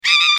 دانلود آهنگ میمون 5 از افکت صوتی انسان و موجودات زنده
دانلود صدای میمون 5 از ساعد نیوز با لینک مستقیم و کیفیت بالا
جلوه های صوتی